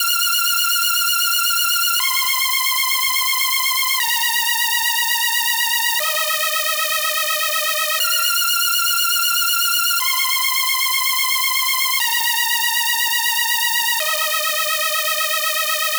TSNRG2 Lead 026.wav